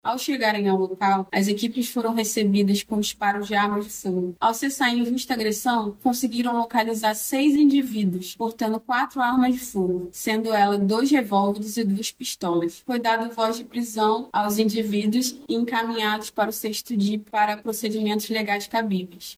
SONORA-PRESOS-ZONA-NORTE.mp3